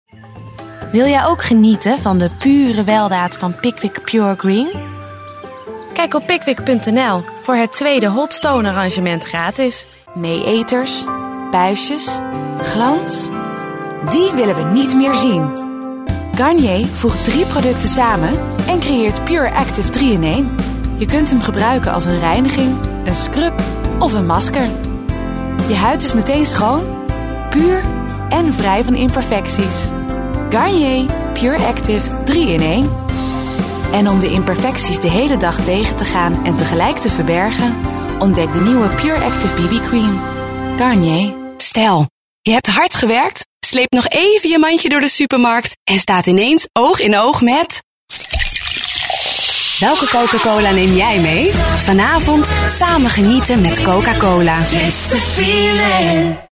Fresh and feminine!
Female / 30s / Dutch, English
Showreel